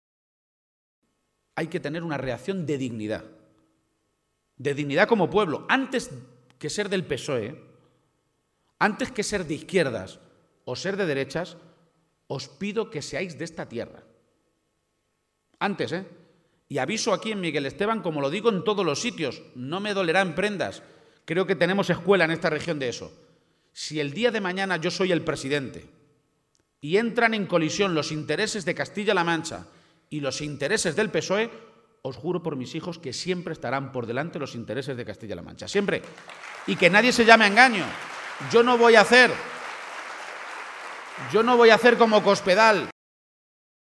En un acto al que han asistido más de 300 personas en la localidad toledana de Miguel Esteban, García-Page ha pedido con mucha rotundidad que “antes que ser del PSOE, antes que ser de izquierdas o de derechas, seáis de esta tierra”.